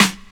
Boom-Bap Snare 53.wav